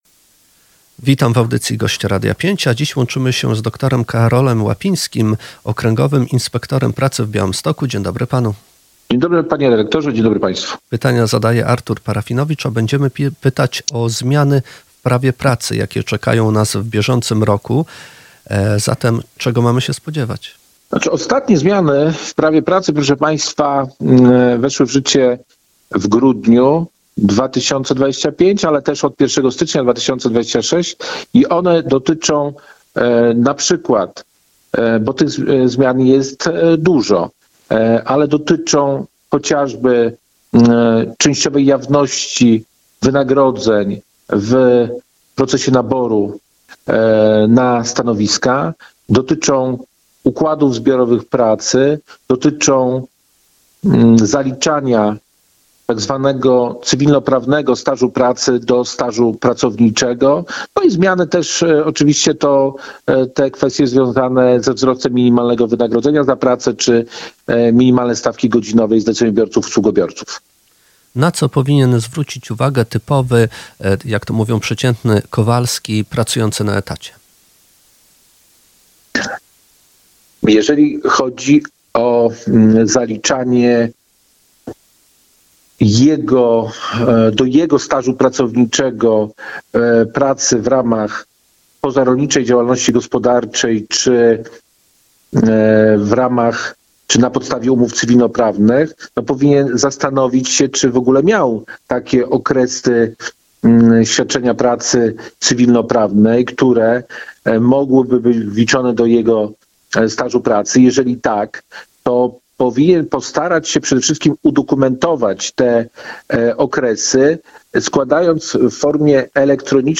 Więcej o zmianach w Radiu 5 dr Karol Łapiński, Okręgowy Inspektor Pracy w Białymstoku.